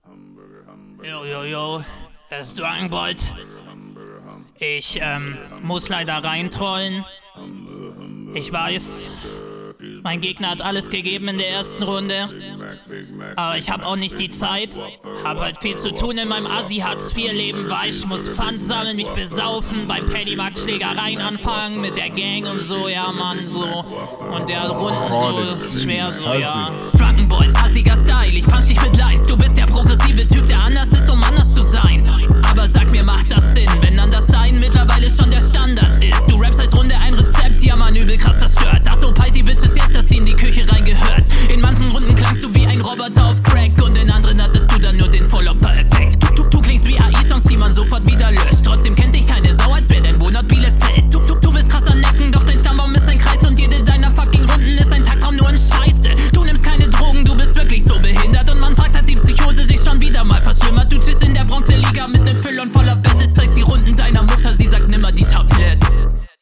Flow ist gut, keine ganz Okey noch viel Luft nach oben!